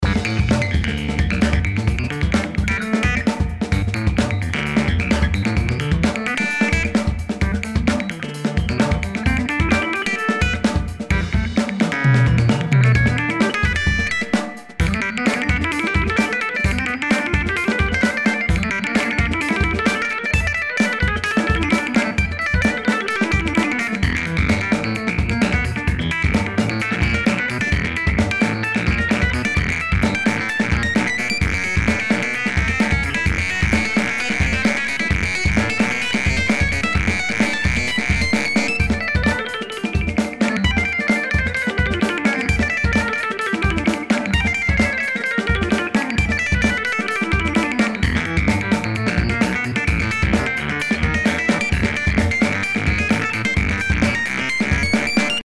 Hier tritt das Vintage Vibe EP mit einem Wah auf:
Die Drums stammen von EZdrummer, Funkmaster.
Das mit der Kanne Kaffee war gelogen: Die Pattern sind aus der Funk-Erweiterung für EZdrummer und wurden im Originaltempo von 105 BPM eingespielt. Unser Audiodemo läuft mit 130 BPM.